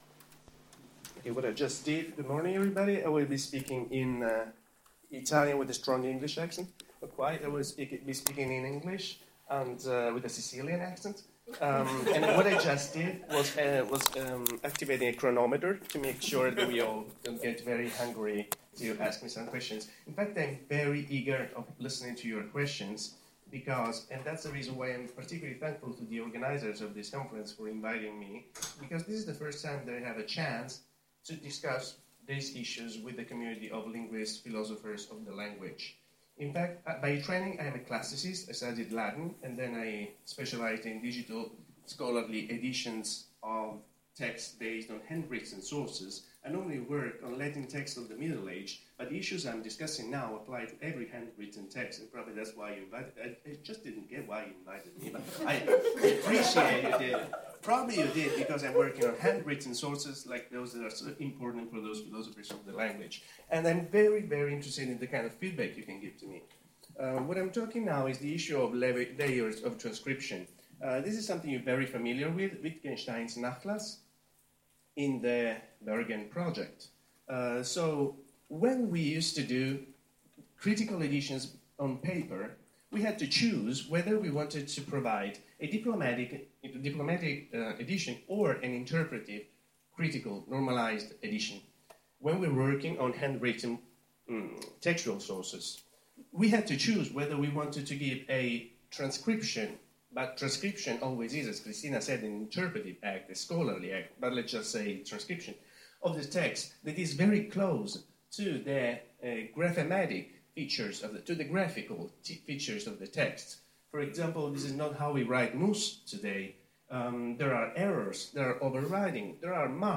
Talk on a multi-layer model for digital scolarly editions